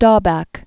(stôbăk), Roger Thomas Born 1942.